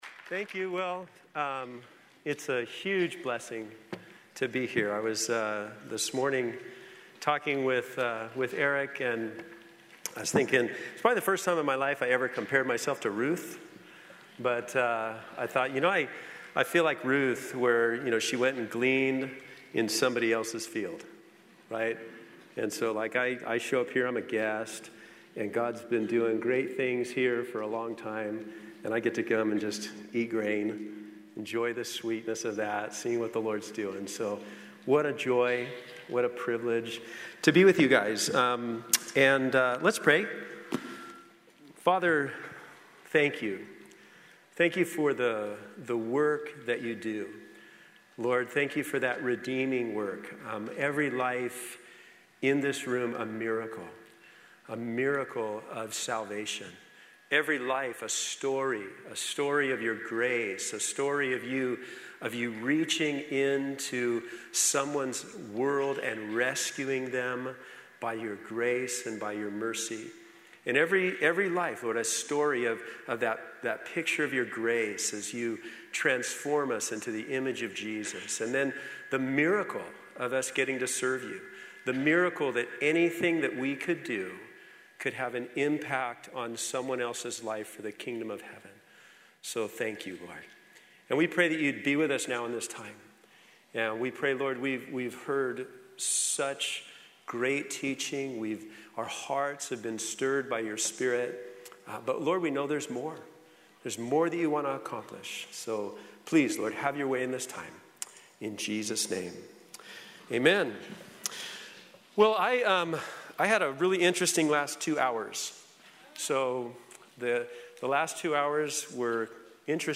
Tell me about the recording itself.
Southwest Pastors and Leaders Conference 2017